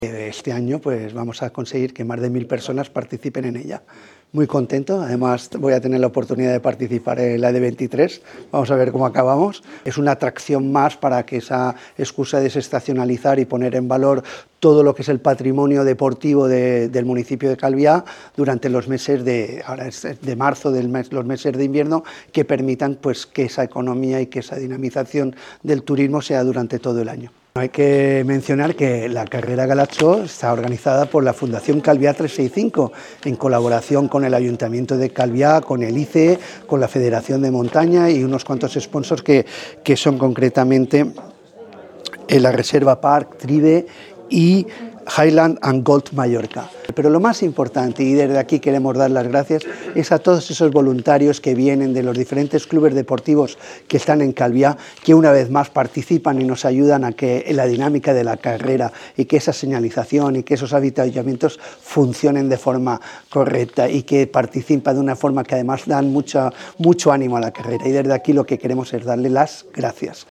mayor-juan-antonio-amengual-statements.mp3